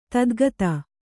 ♪ tadgata